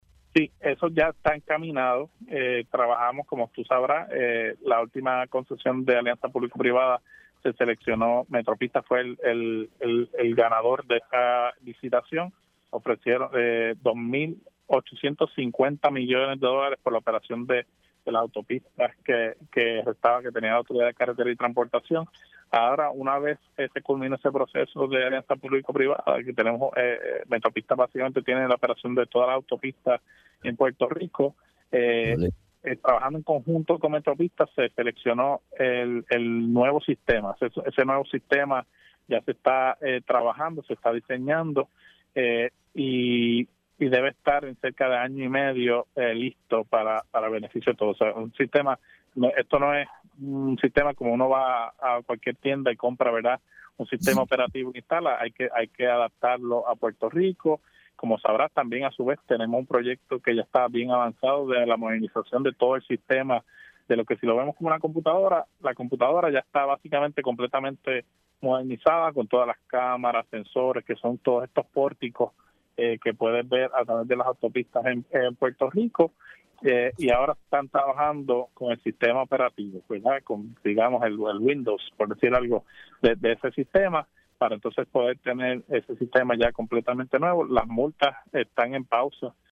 El secretario del Departamento de Transportación y Obras Públicas (DTOP), Edwin González confirmó en Pega’os en la Mañana que los trabajos en el Expreso Luis A. Ferré (PR-52) están encaminados para concluir a principios del 2026.